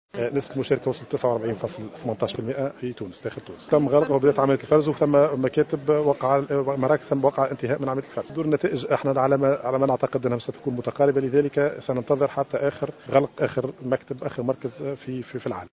أفاد عضو الهيئة العليا المستقلة للانتخابات رياض بوحوشي، في تصريح لمراسلة الجوهرة أف أم، ادلى به في قصر المؤتمرات بالعاصمة، بأن نسبة المشاركة في الدورة الثانية للانتخابات الرئاسية بلغت 49.18%، الى حدود الساعة الخامسة وعشر دقائق من عشية اليوم.